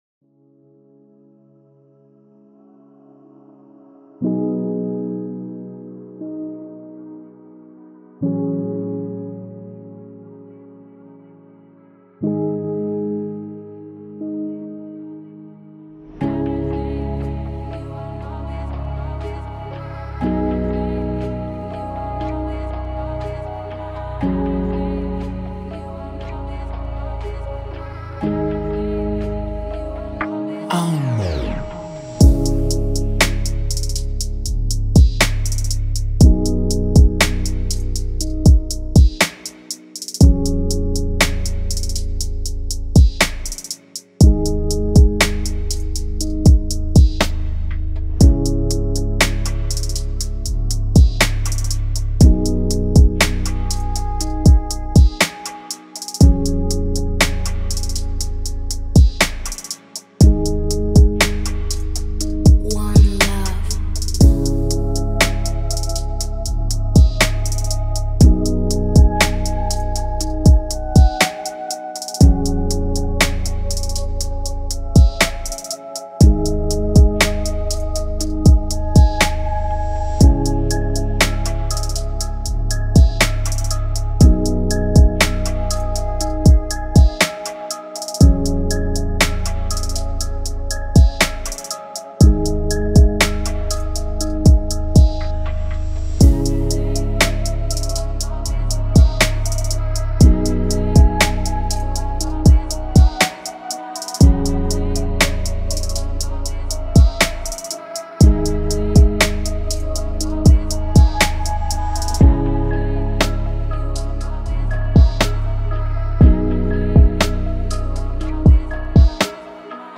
type Beat
Бит в стиле